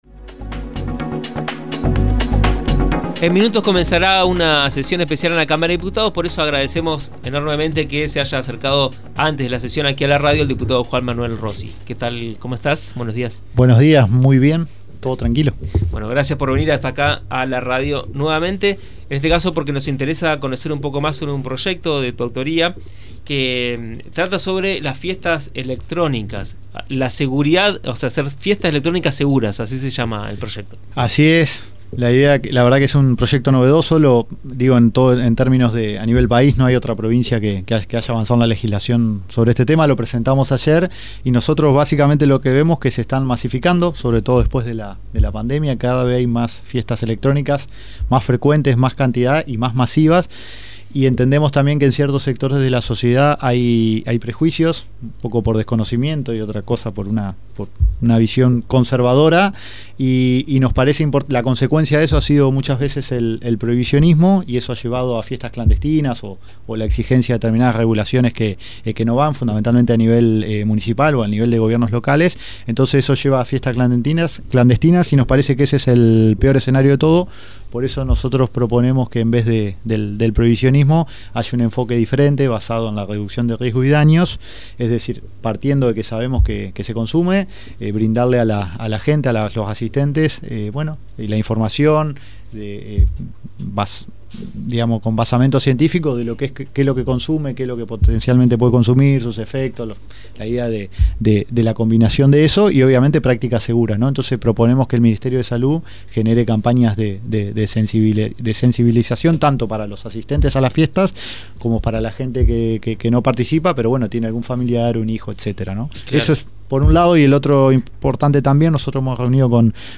La consecuencia de esto es el prohibicionismo, que ha llevado a fiestas clandestinas, que es el peor escenario de todos”, argumentó el diputado del Partido Socialista en diálogo con Radio Diputados.
Juan Manuel Rossi – Dip. Provincial